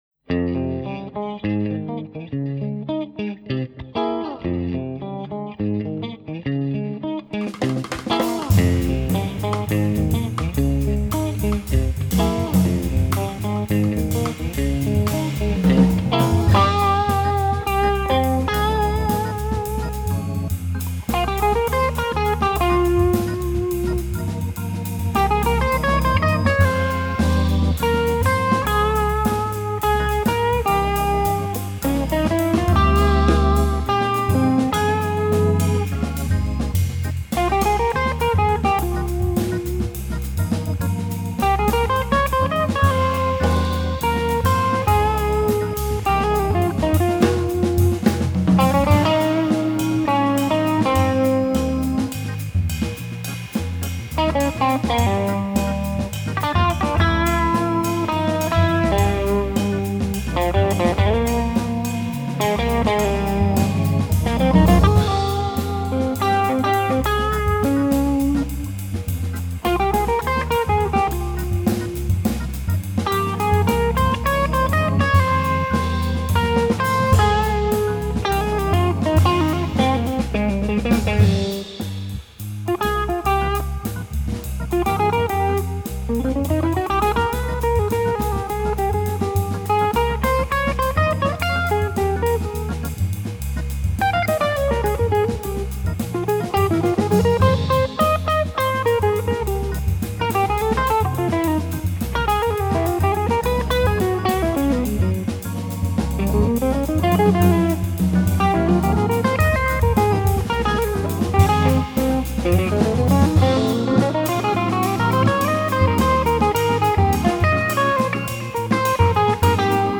guitarra
teclado
batería